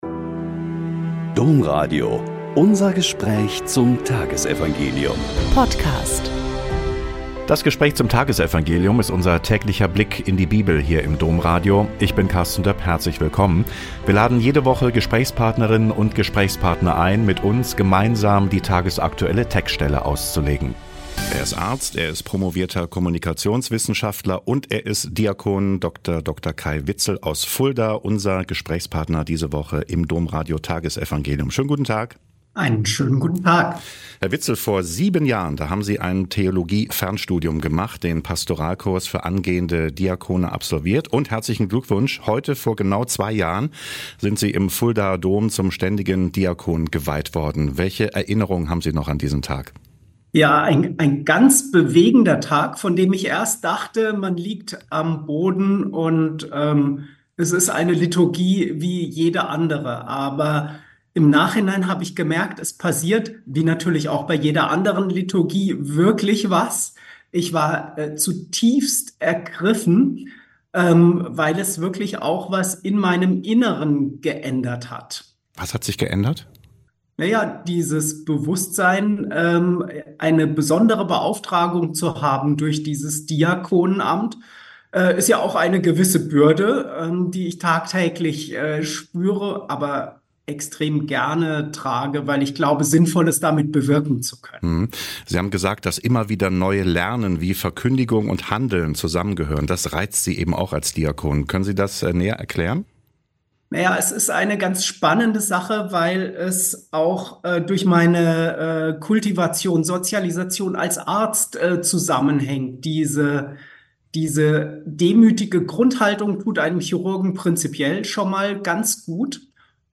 Joh 16,5-11 - Gespräch